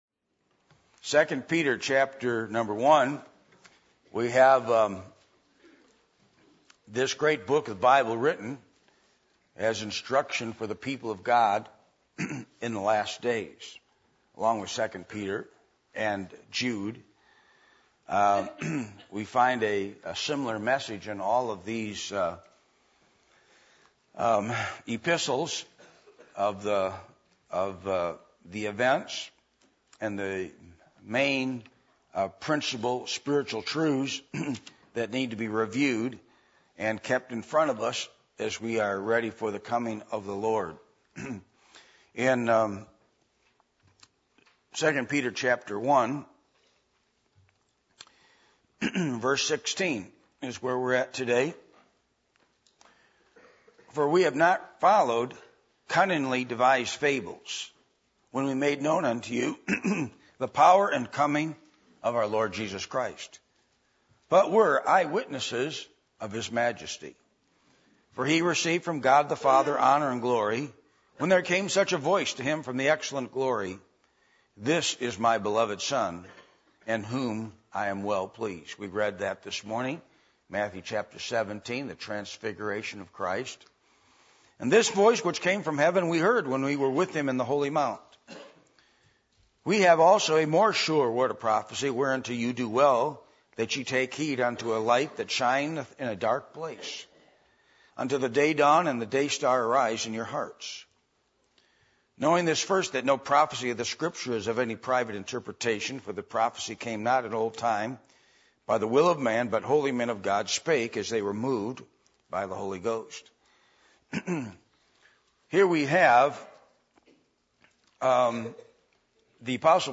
2 Peter 1:16-21 Service Type: Sunday Morning %todo_render% « Is Disney Christian Family Friendly?